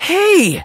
sandy_hurt_vo_02.ogg